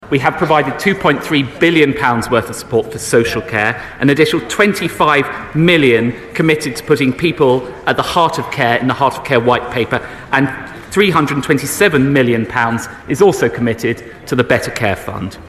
deputy PM Oliver Dowden at PMQ's today